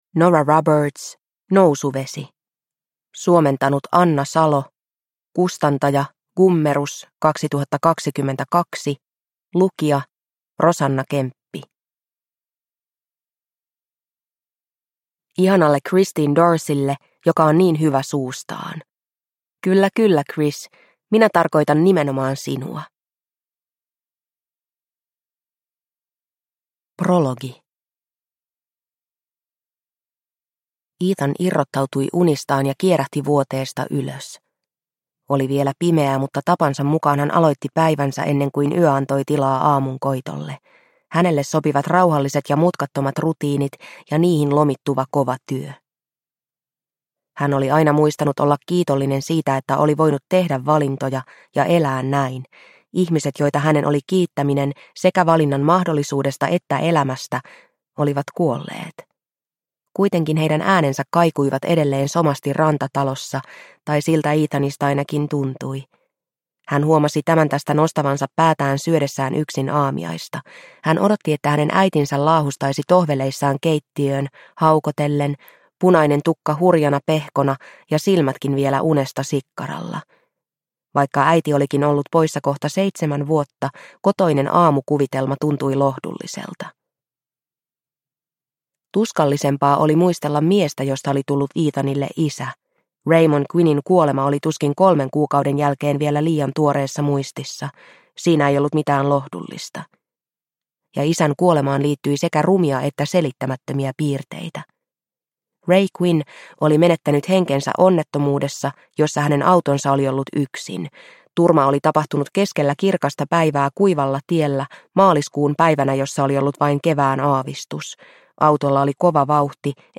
Nousuvesi – Ljudbok – Laddas ner